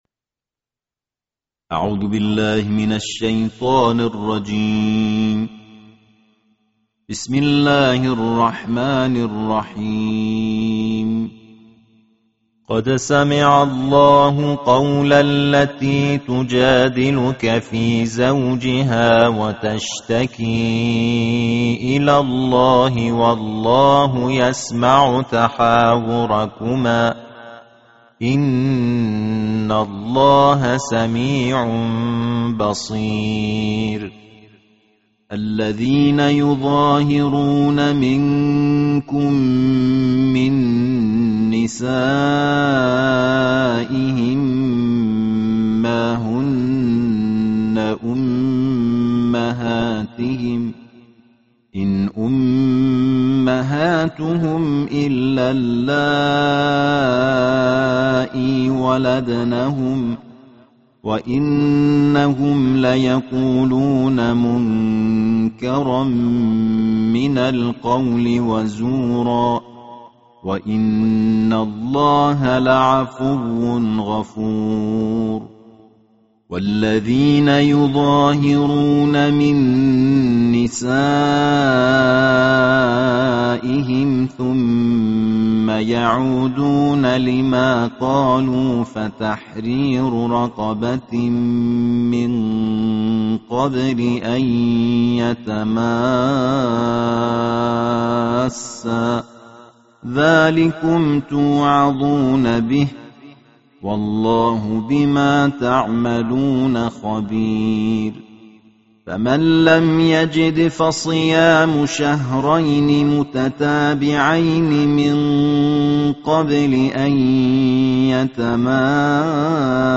Tartil Juz Ke-28 Alquran